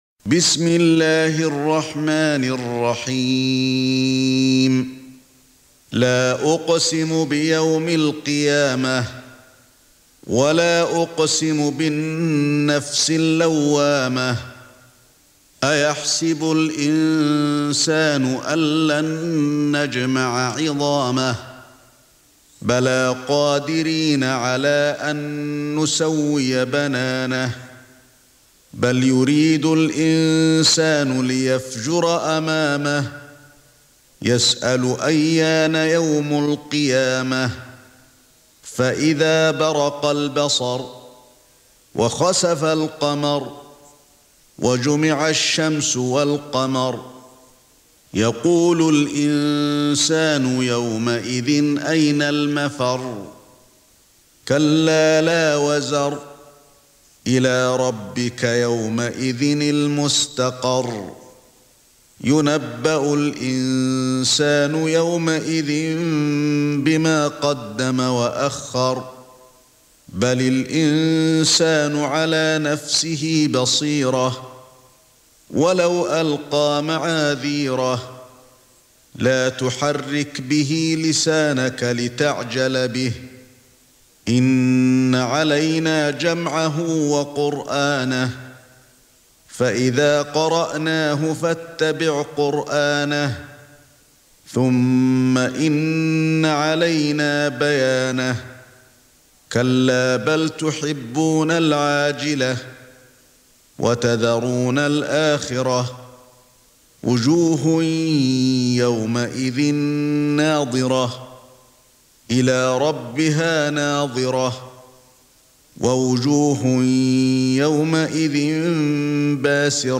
سورة القيامة ( برواية قالون ) > مصحف الشيخ علي الحذيفي ( رواية قالون ) > المصحف - تلاوات الحرمين